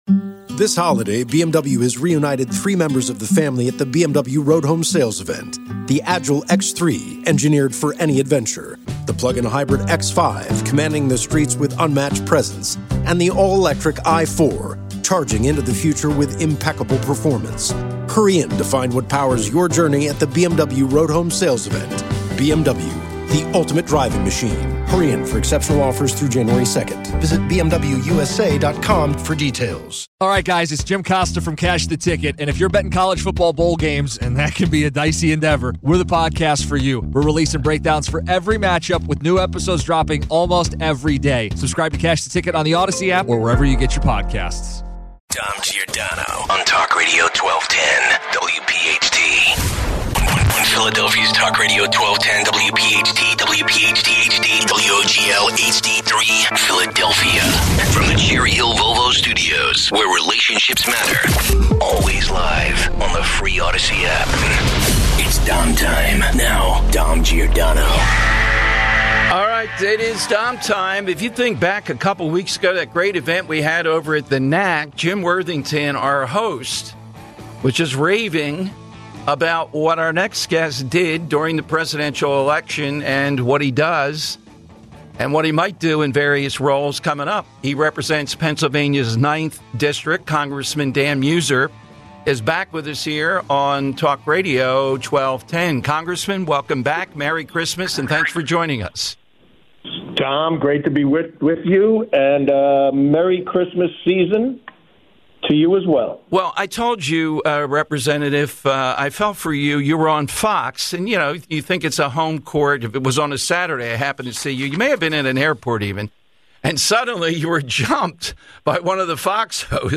1 - PA Congressman Dan Meuser joins us today. Dan details how Trump’s agenda is sending a message to the world that we mean business, economically.
135 - Senator Mike Testa rejoins the program.
Your calls.